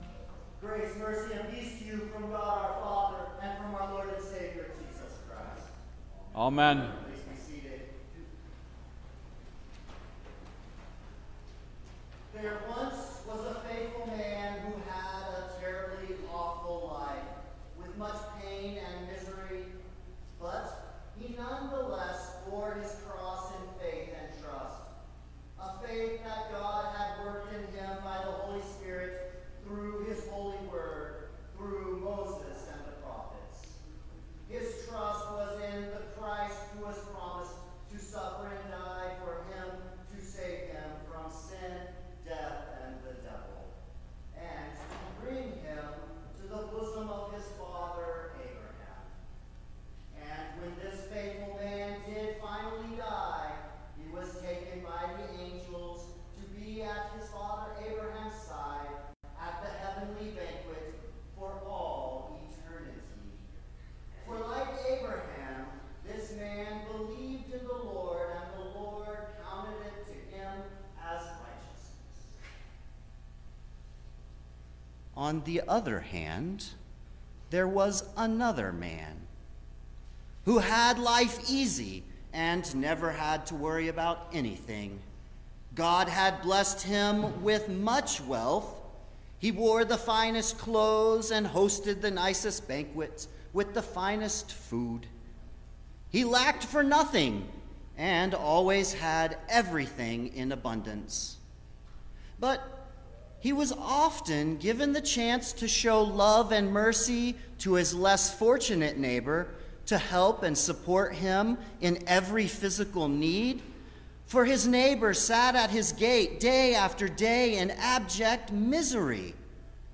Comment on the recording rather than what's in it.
Audio is very quiet until the 1 minute 15 second mark in the sermon, then the sound resumes as normal.